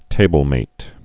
(tābəl-māt)